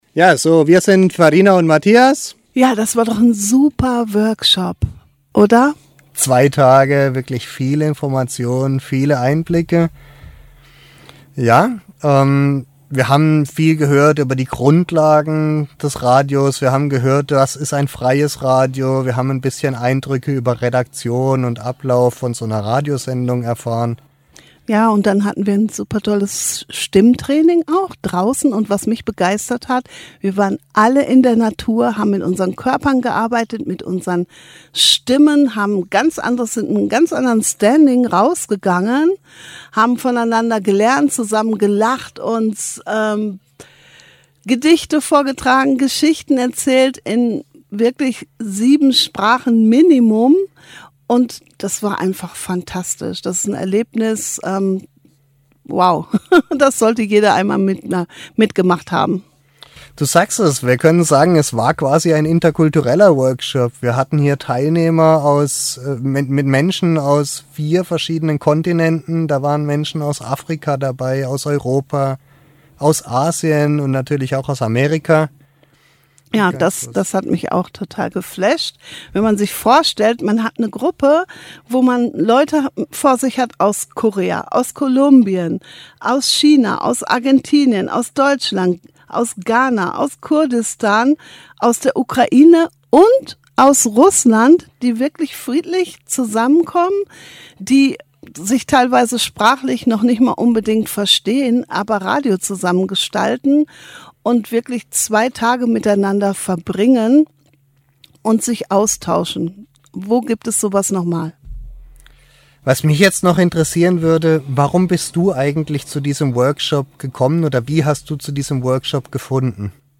Finally, the editorial team prepared and performed a one-hour radio broadcast.